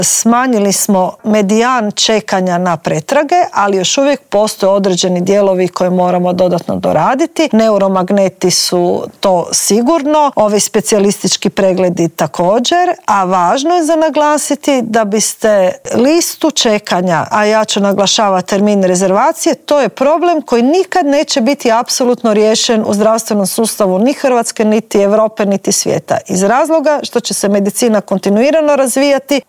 ZAGREB - S ministricom zdravstva razgovarali smo o listama čekanja, s kandidatom za gradonačelnika Pule o stanju u gradu, a u studiju Media servisa ugostili smo još zanimljivih sugovornika.
S ministricom zdravstva Irenom Hrstić u Intervjuu tjedna Media servisa razgovarali smo o intervencijama na Malom Lošinju i Lastovu, ali i listama čekanja: